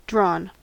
Ääntäminen
Ääntäminen US Tuntematon aksentti: IPA : /drɔːn/